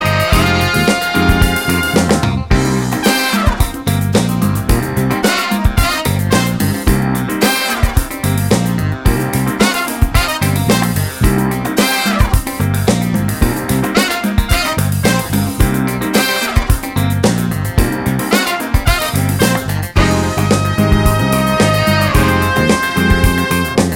no Backing Vocals Disco 3:10 Buy £1.50